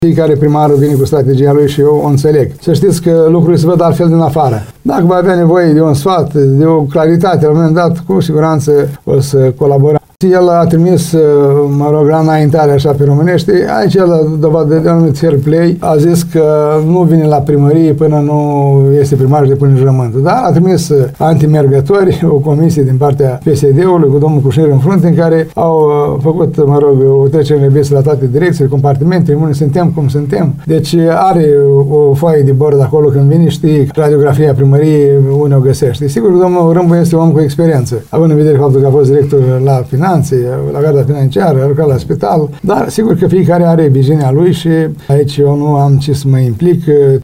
LUNGU a declarat postului nostru că a avut discuții cu o delegație a viitorului edil, căreia i-a prezentat date referitoare la situația financiară a instituției.